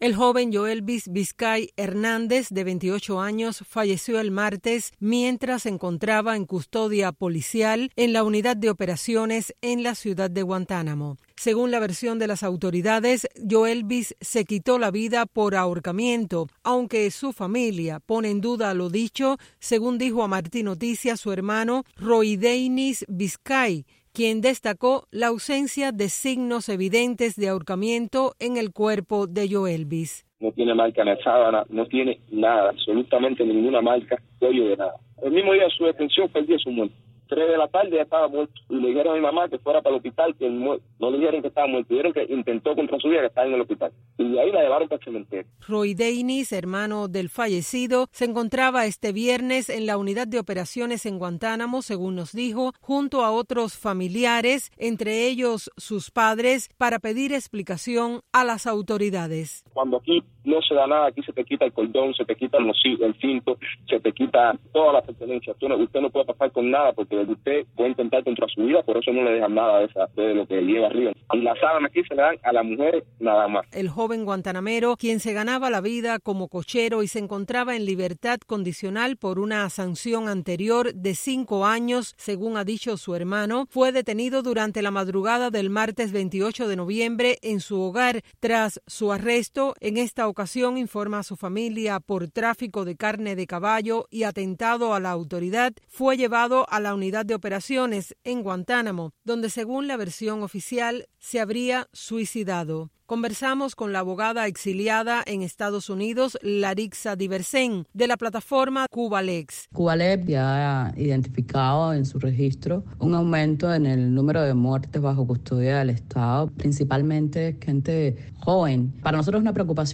abogada cubana exiliada.